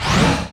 snd_sliding_door_open.wav